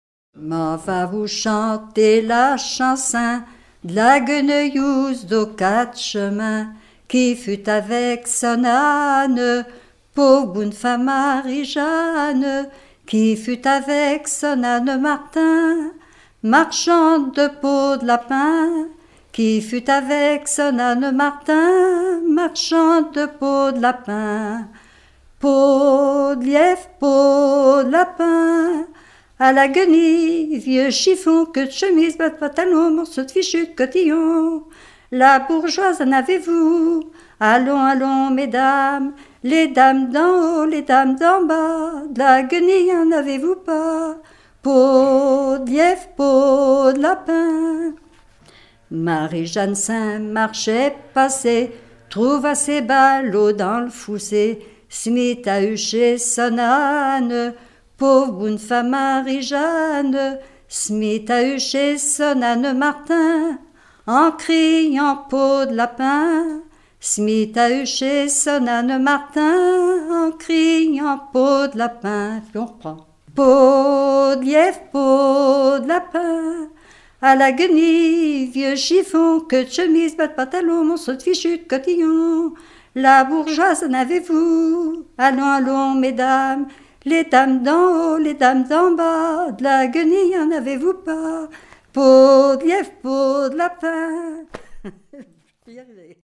Plaine vendéenne
Genre strophique
Pièce musicale éditée